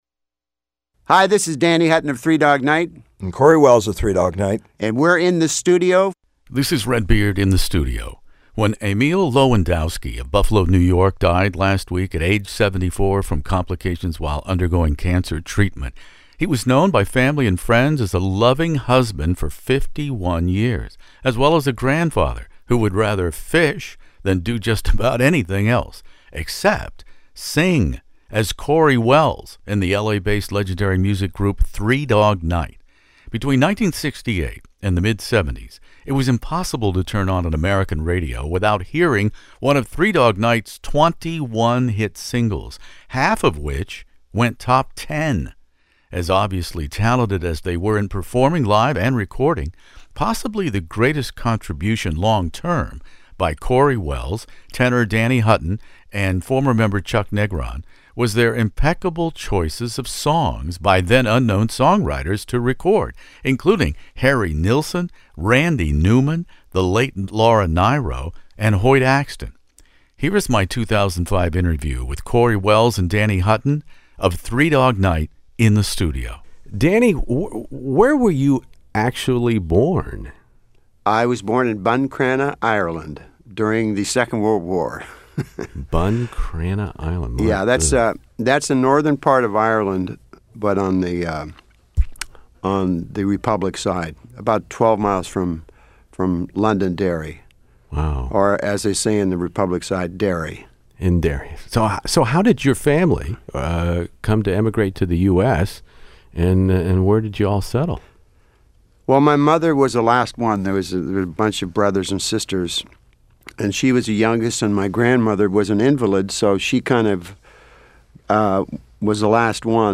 Interview with Three Dog Night's Danny Hutton & the late Corey Wells